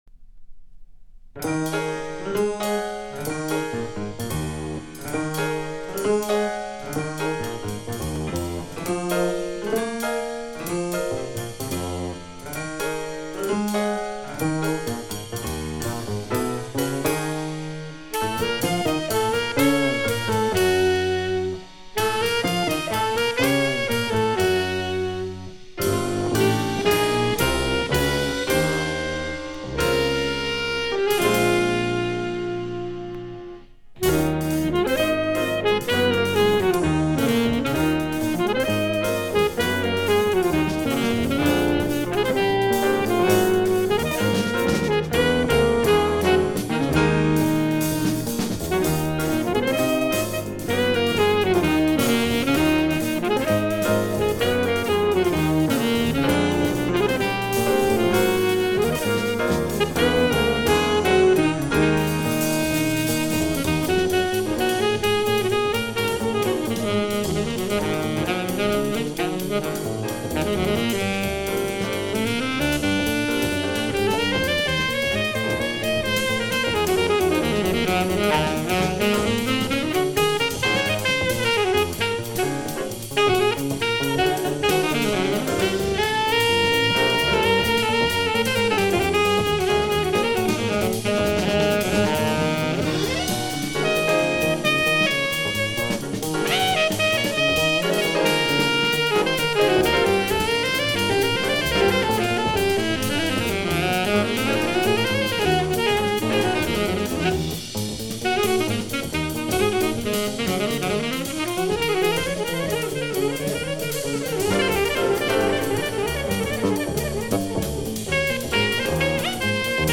Saxo alto, piano, guitare, bass, drums / saxo & drums solos